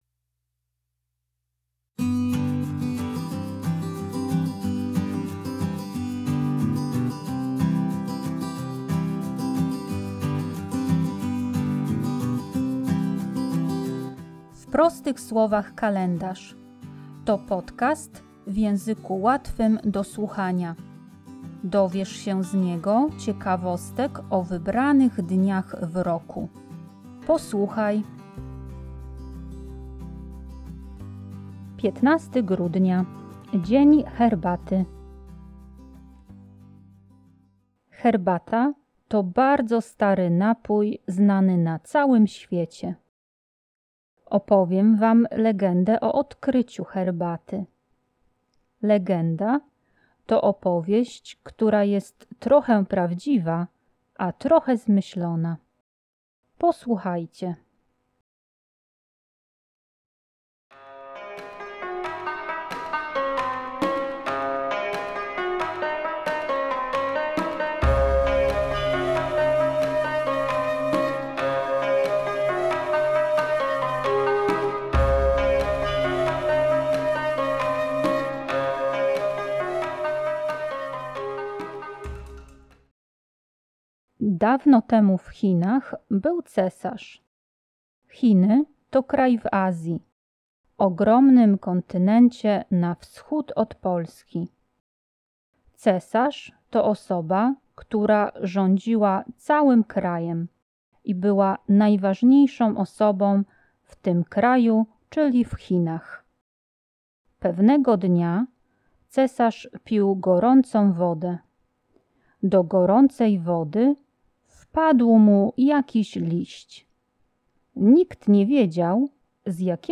W podcaście usłyszycie dźwięki tradycyjnej muzyki chińskiej.